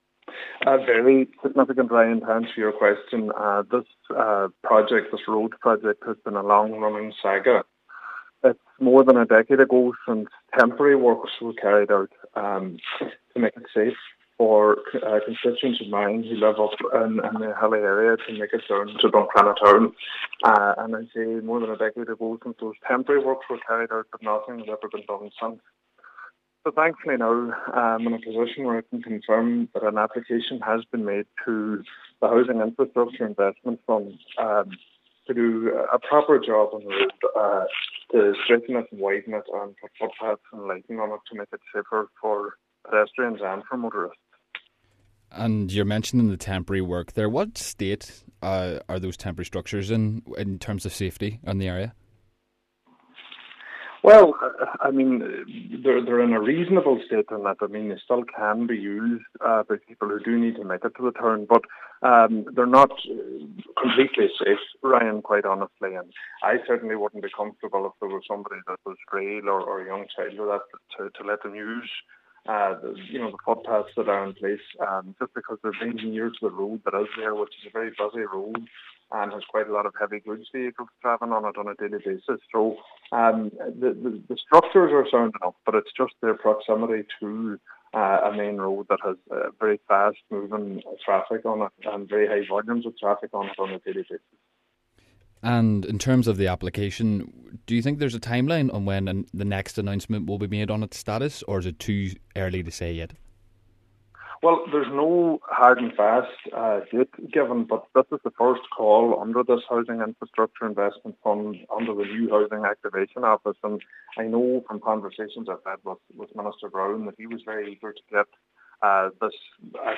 Cllr Bradley says the application is significant: